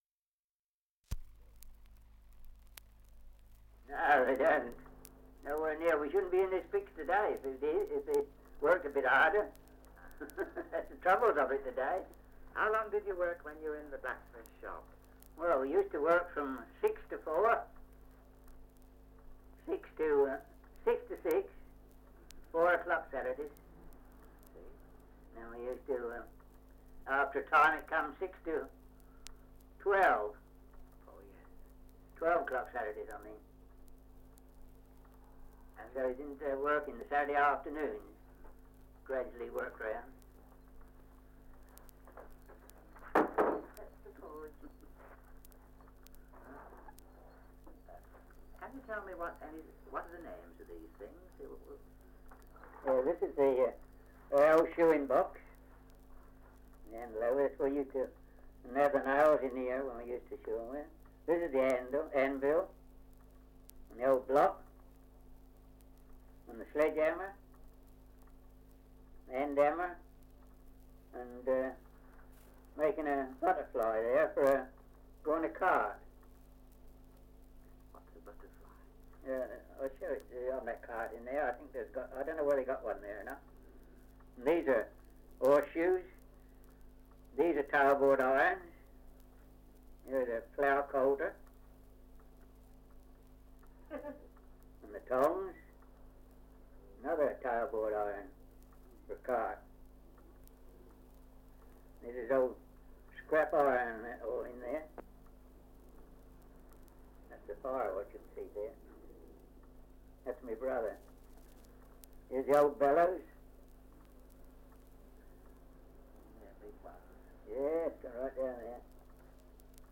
Survey of English Dialects recording in Farningham, Kent
78 r.p.m., cellulose nitrate on aluminium